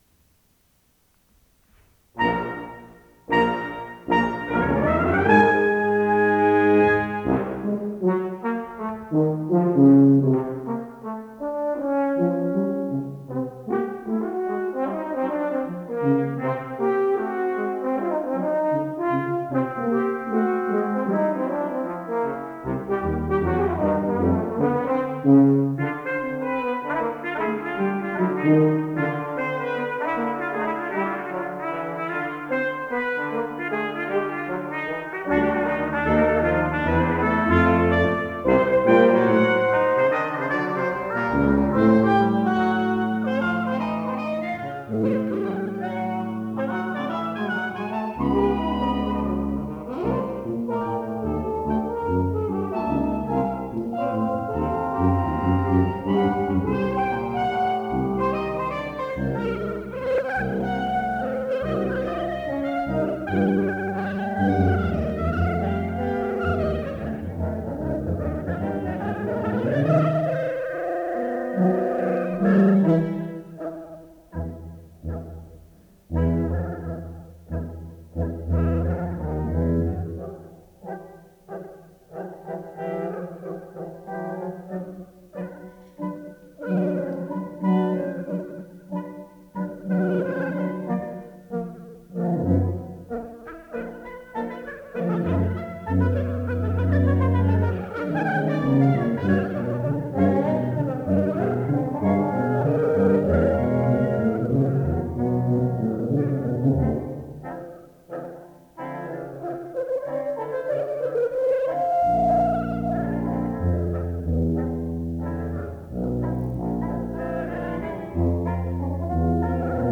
2002 8:00 p.m. Hirsch Orchestra Rehearsal Hall